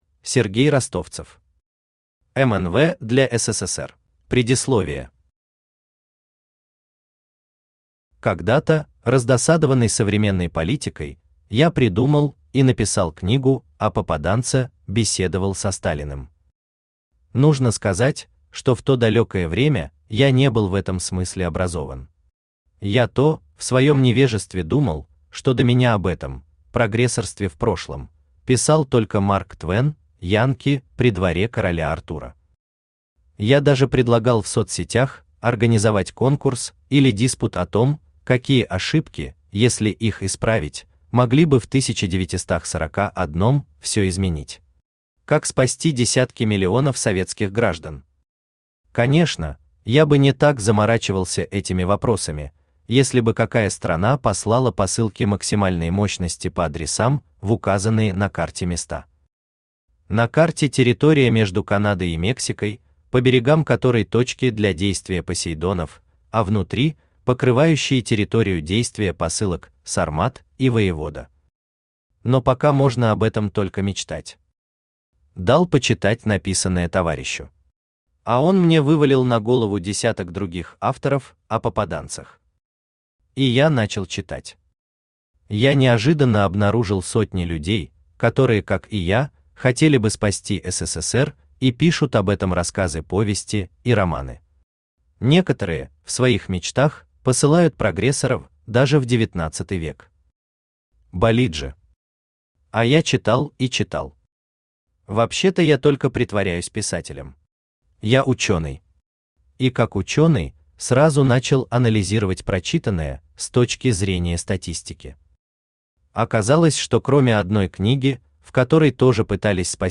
Аудиокнига МНВ для СССР | Библиотека аудиокниг
Aудиокнига МНВ для СССР Автор Сергей Юрьевич Ростовцев Читает аудиокнигу Авточтец ЛитРес.